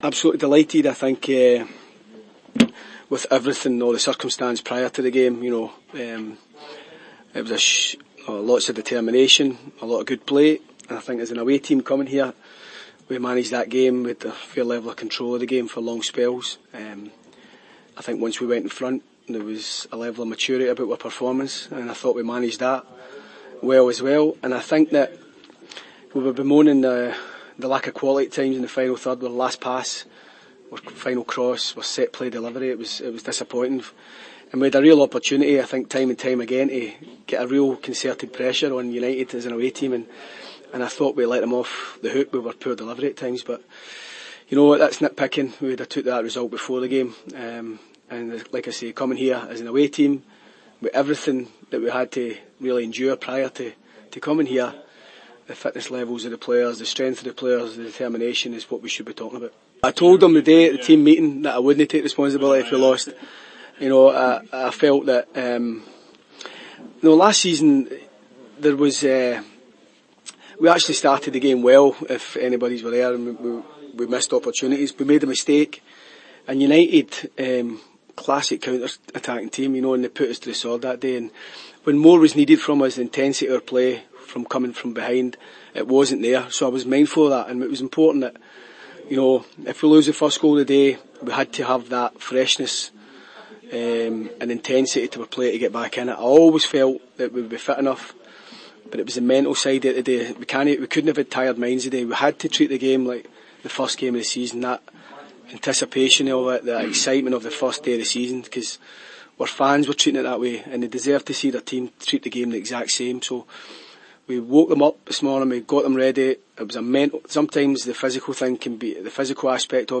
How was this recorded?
at the Tannadice post match media conference.